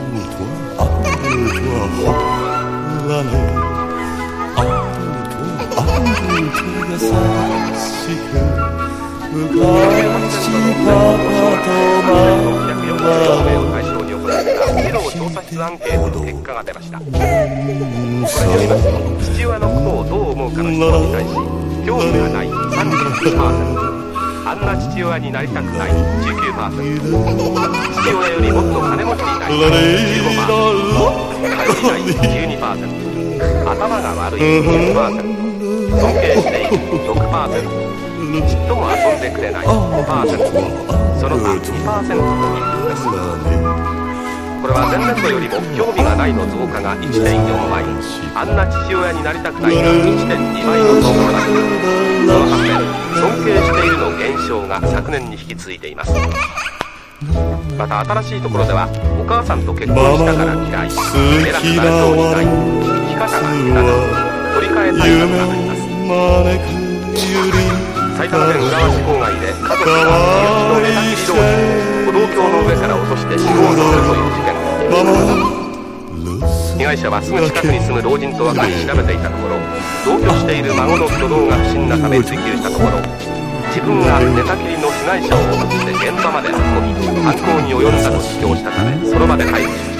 和モノ・エキセントリック歌謡！